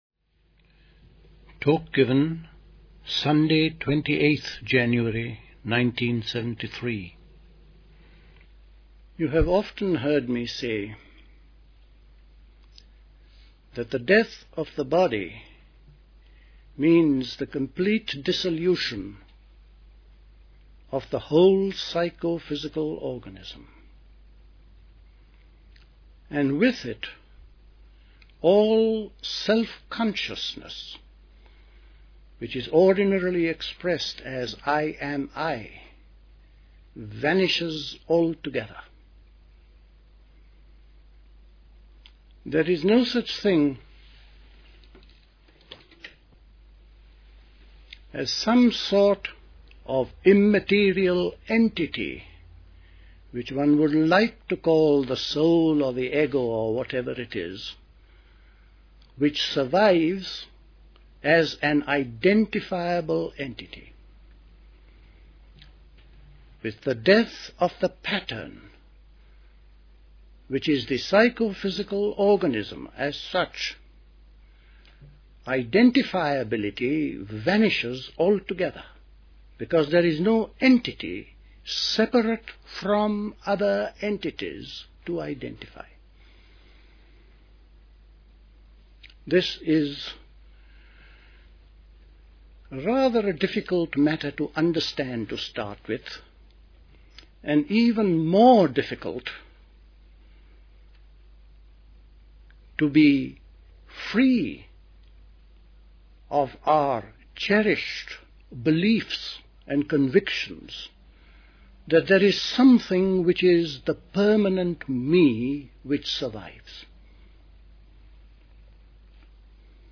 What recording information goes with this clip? at Dilkusha, Forest Hill, London on 28th January 1973